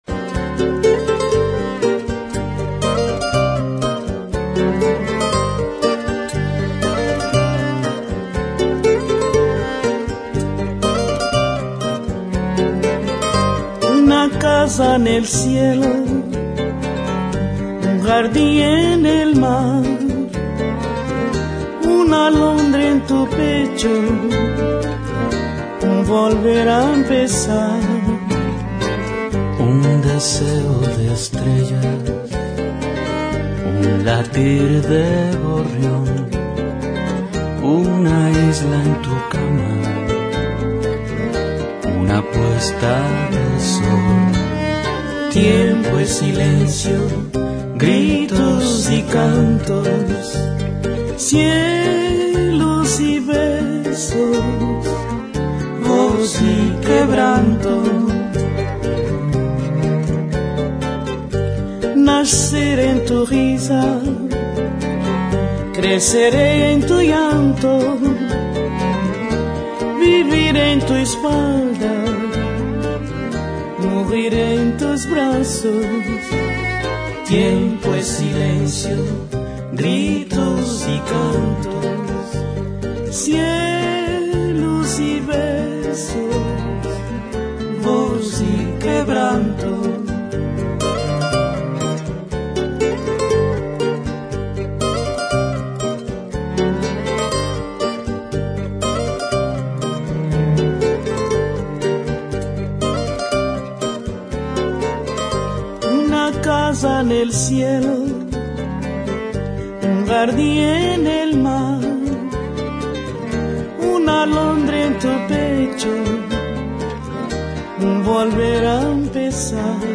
El cantautor español Luis Pastor se presentará esta semana en La Plata, en el marco de su primera gira por la Argentina. Antes dialogó con La Cofradía por Radio Universidad.